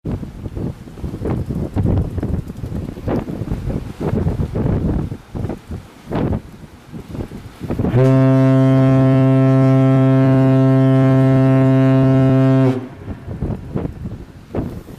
دانلود صدای بوق کشتی 5 از ساعد نیوز با لینک مستقیم و کیفیت بالا
جلوه های صوتی